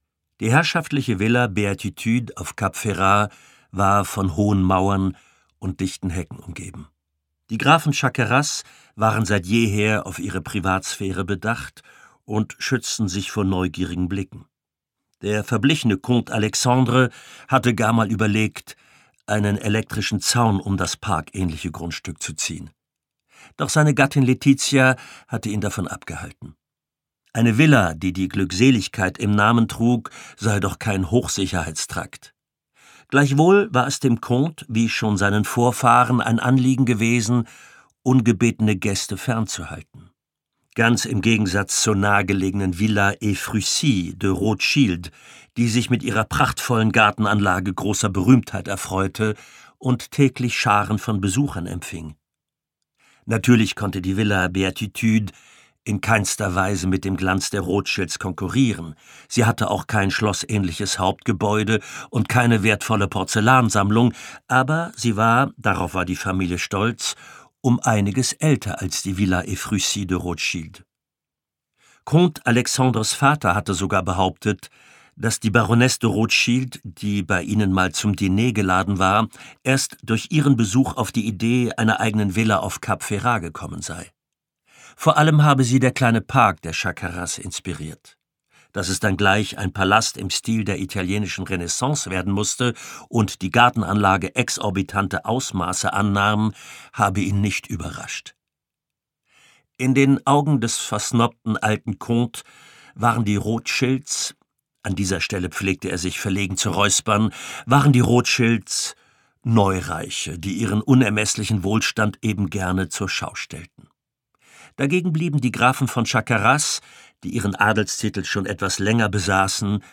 Monsieur le Comte und die Kunst der Täuschung Kriminalroman | Band 2 der Cosy-Crime- und Spiegel-Bestseller-Krimi-Reihe Pierre Martin (Autor) Wolfram Koch (Sprecher) Audio Disc 2024 | 1.